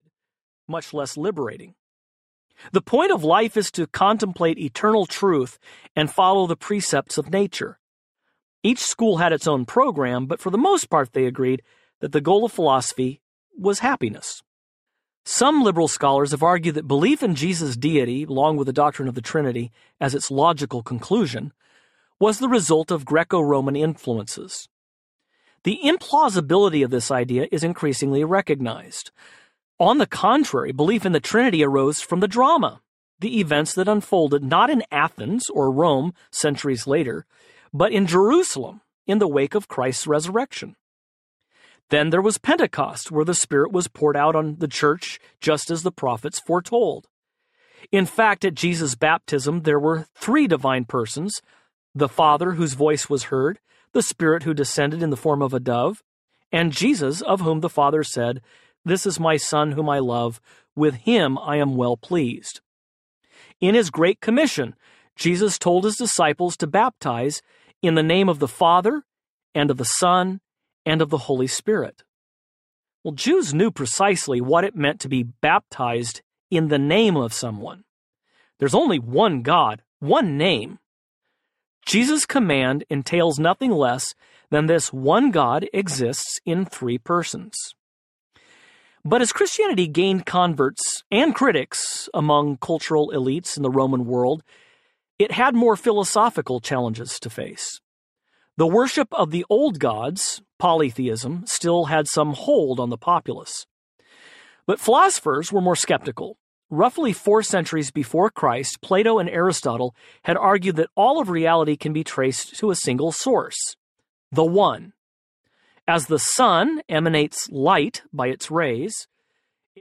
Core Christianity Audiobook
Narrator
– Unabridged